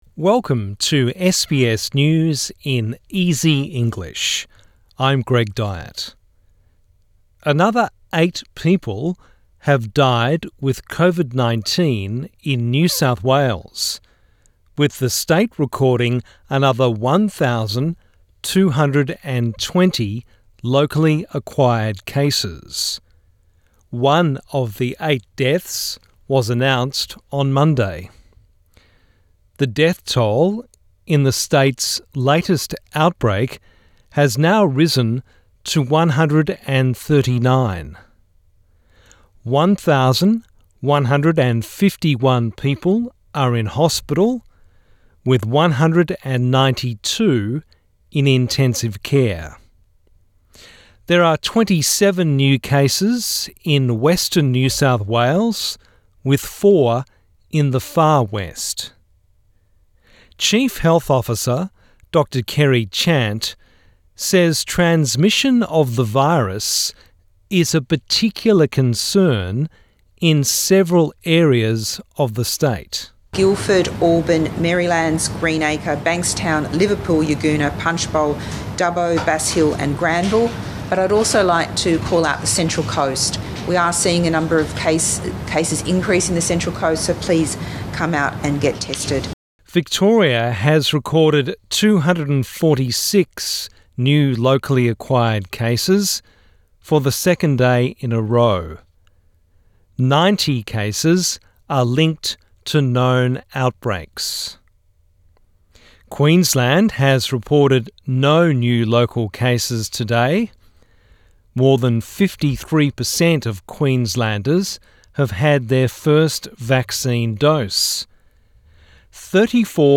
A daily 5 minute news wrap for English learners and people with disability.